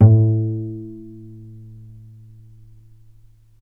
vc_pz-A2-mf.AIF